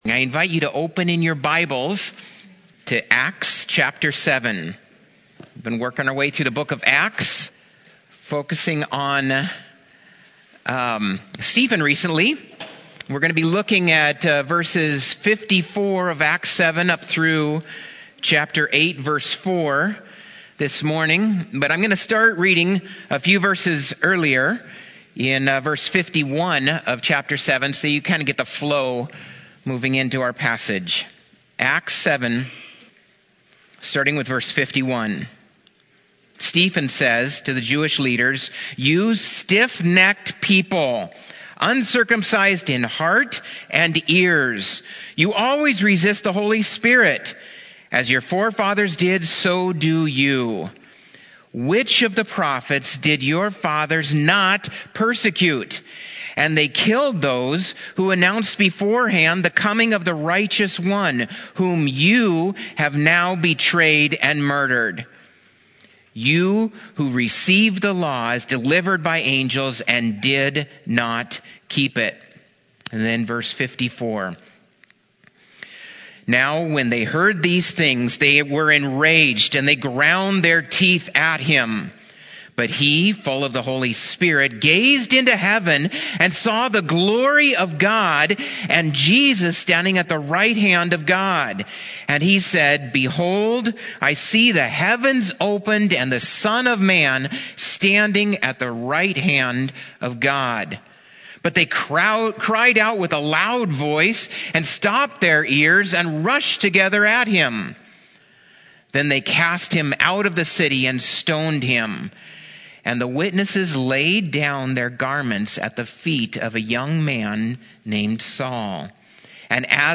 Recently I’ve been invited to preach in local churches during their Sunday morning services.
At Hope Community Church I preached on Psalm 1-2 on April 28 with the main point: Live rightly with God according to His Word and His Son to receive blessing instead of wrath.